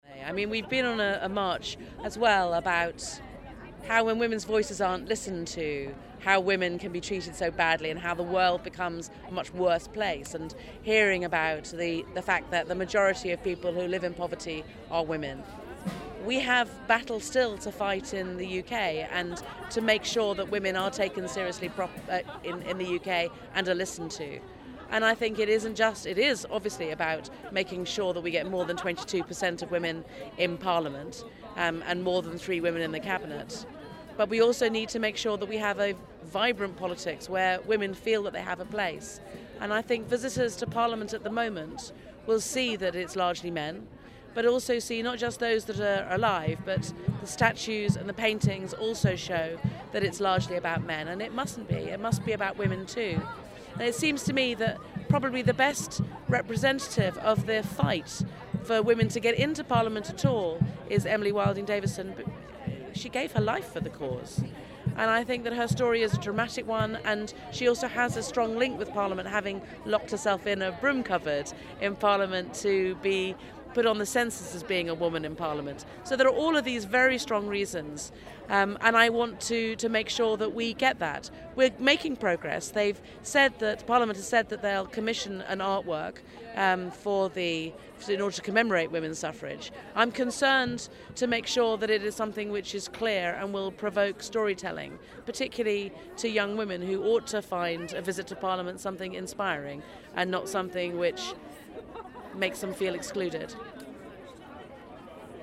If you’re not sure why a statue of suffragette Emily Davison in the Houses of Parliament matters, listen to Emily Thornberry herself make the case:
Emily Thornberry MP, founder of the Emily Davison Statue in Parliament campaign, at the Southbank; International Women’s Day